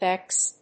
/bɛks(米国英語), beks(英国英語)/